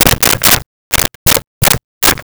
Telephone Dialed 02
Telephone Dialed 02.wav